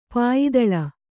♪ pāyidaḷa